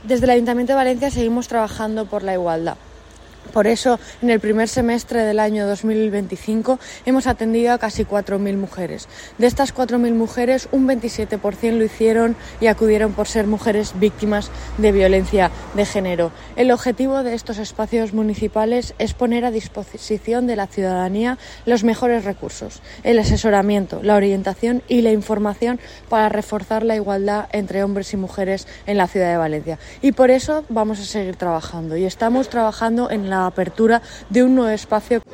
Corte de voz de la concejal de Igualdad, Rocío Gil.